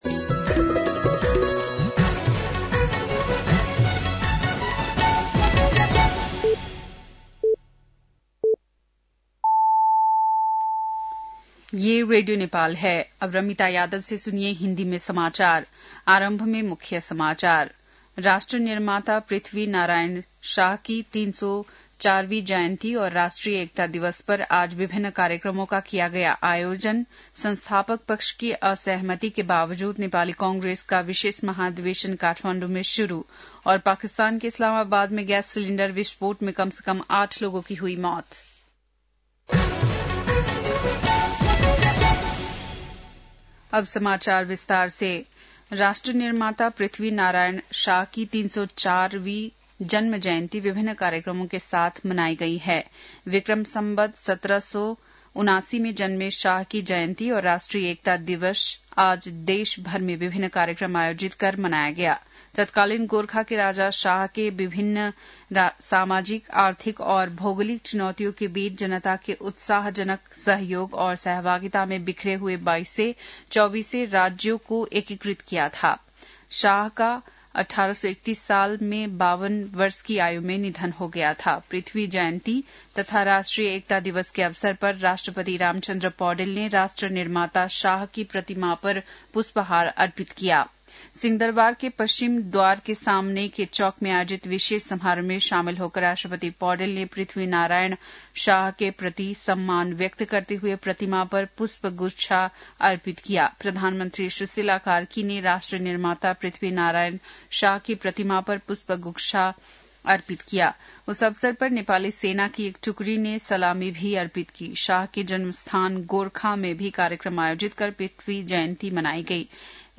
बेलुकी १० बजेको हिन्दी समाचार : २७ पुष , २०८२
10-pm-hindi-news-9-27.mp3